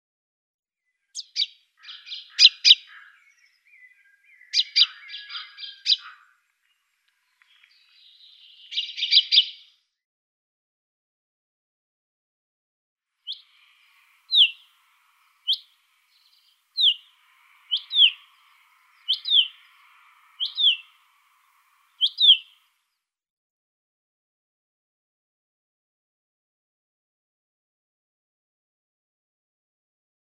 نام فارسی: سهره جنگلی
نام انگلیسی: Common Chaffinch
آواز:
02b.Common Chaffinch.mp3